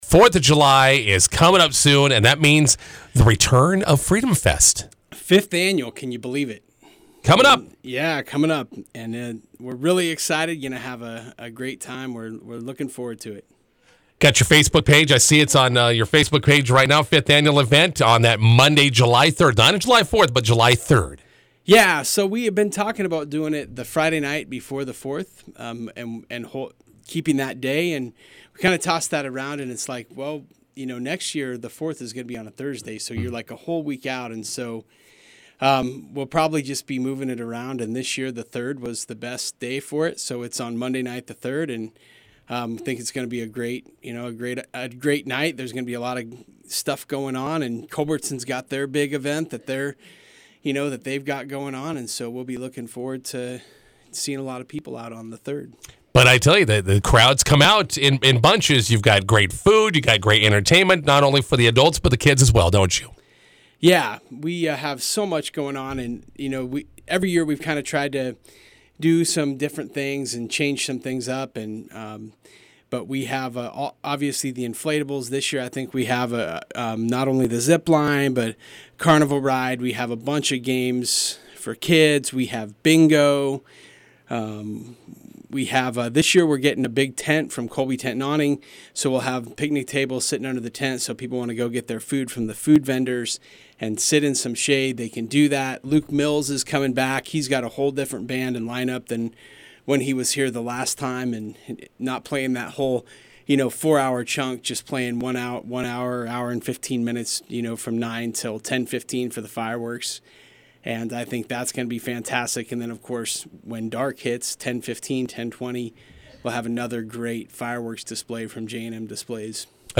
INTERVIEW: Freedom Fest set for Monday at the Red Willow County Fairgrounds.